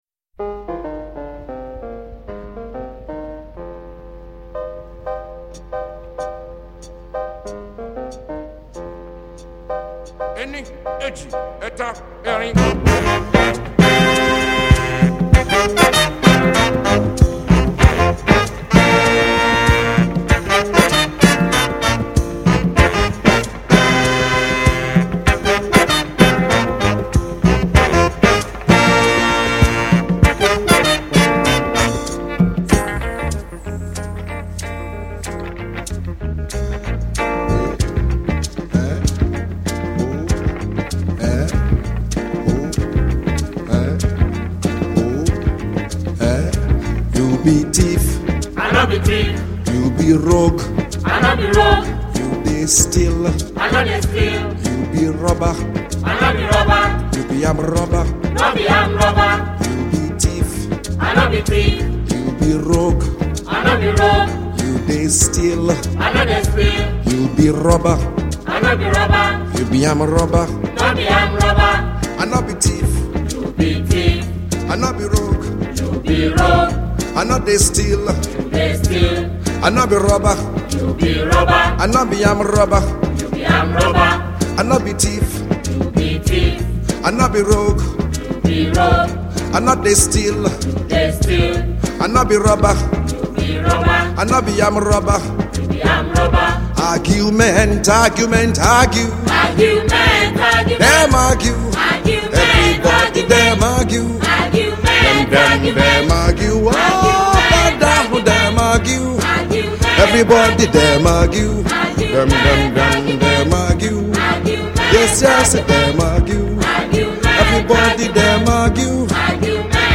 a talented and charismatic creator of Afrobeat.